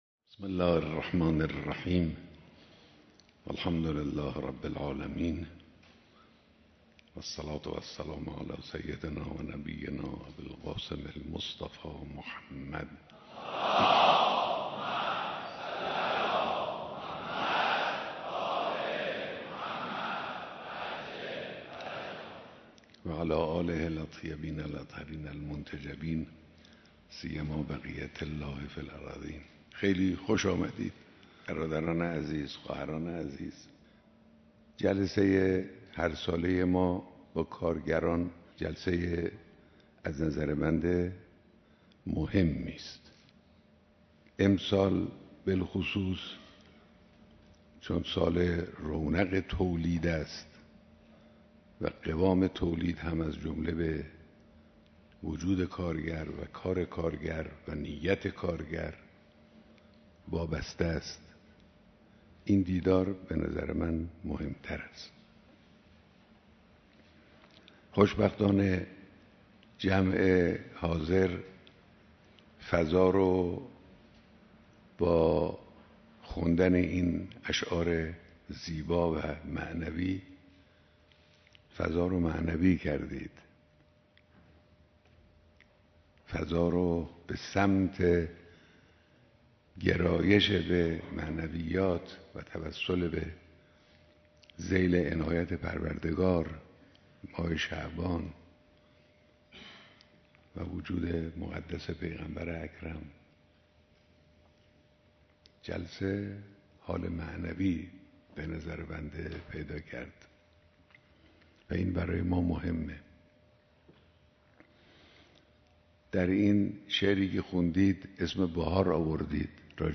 بیانات در دیدار هزاران نفر از کارگران